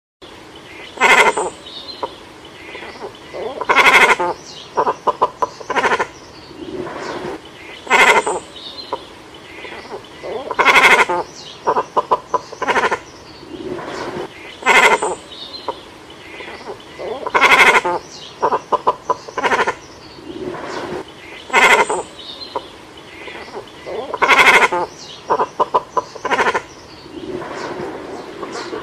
The Sounds of Black Murdocks
Black-Murdock-Calls.mp3